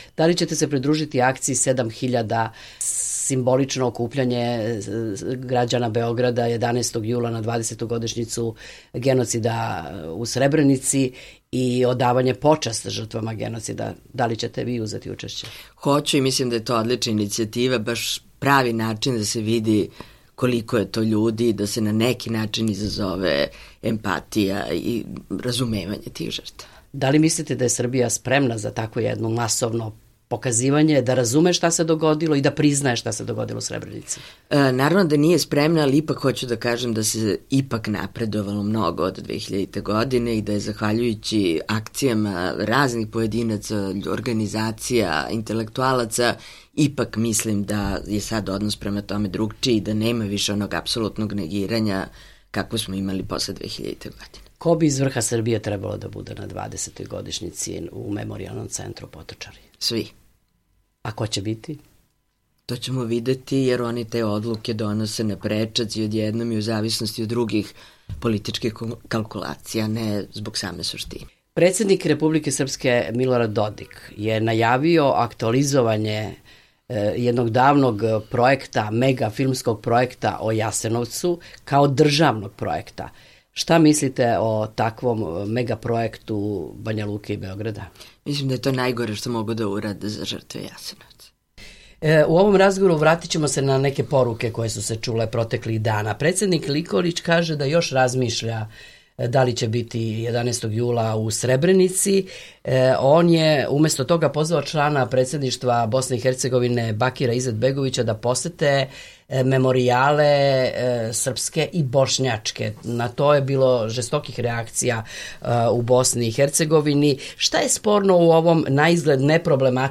Intervju: Dubravka Stojanović